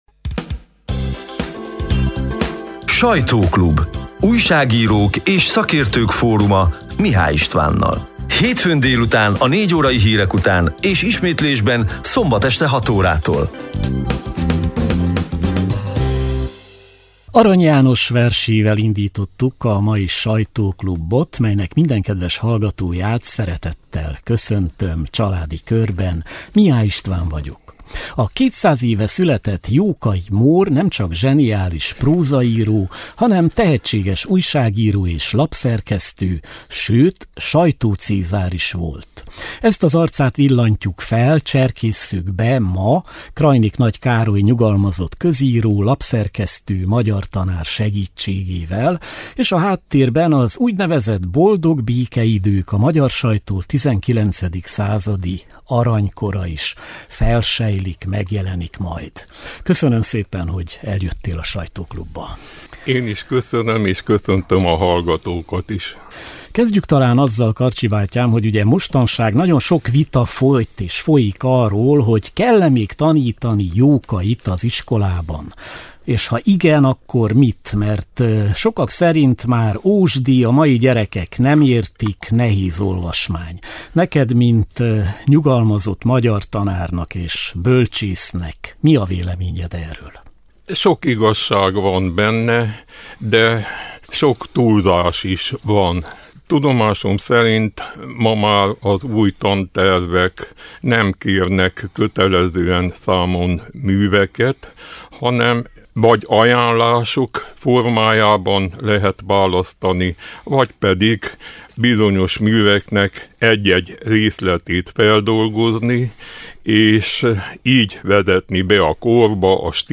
A lejátszóra kattintva az április 14-i, hétfő délutáni élő műsor szerkesztett, kissé rövidített változatát hallgathatják meg.